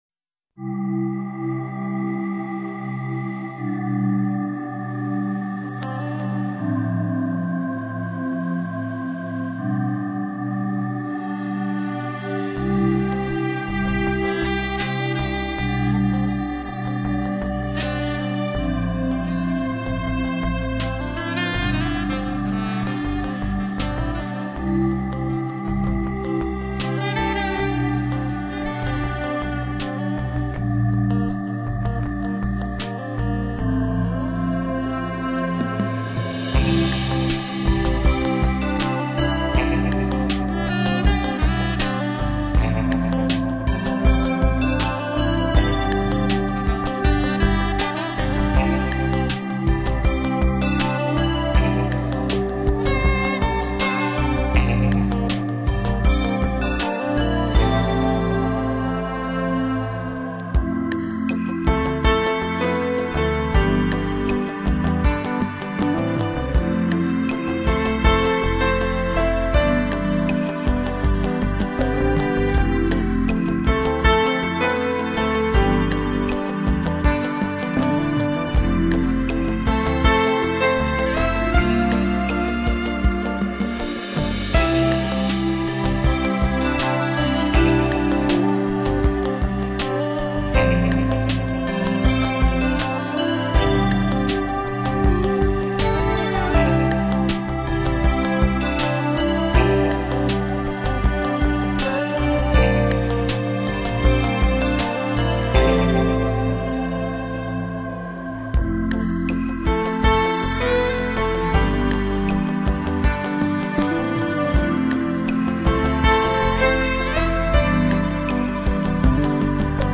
类型：NewAge